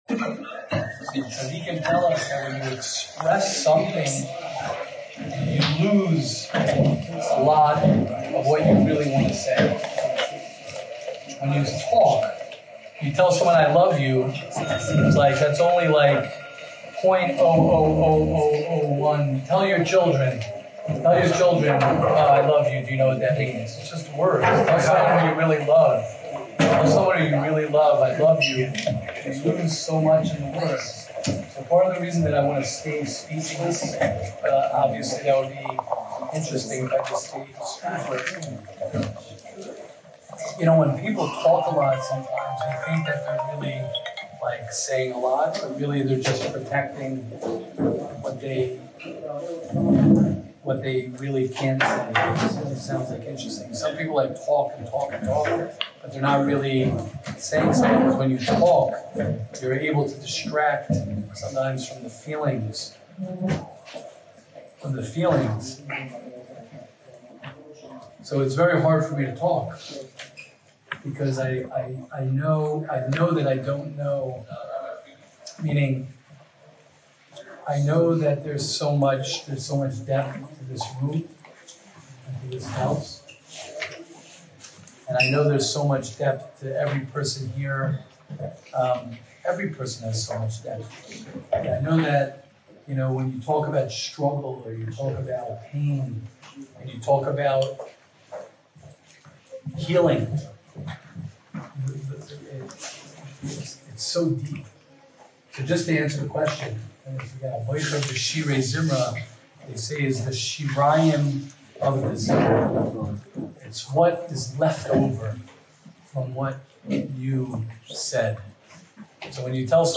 Shiur at “The Neighbors” in Monsey